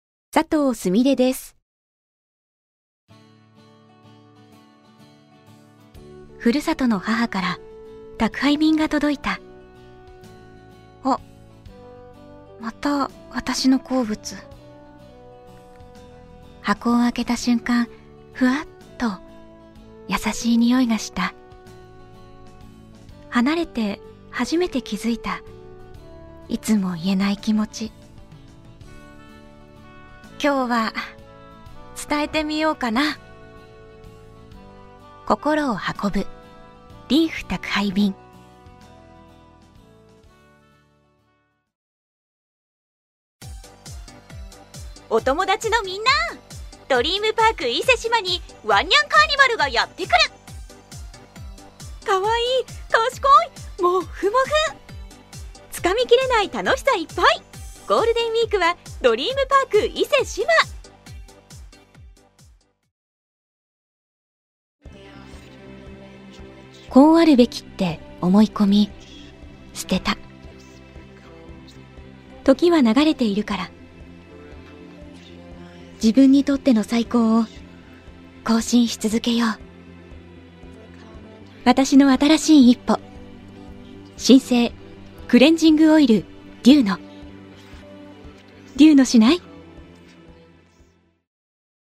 ボイスサンプル
• ピュアな透明感
• 音域：高～中音
• 声の特徴：優しい、おだやか、ピュア
• CM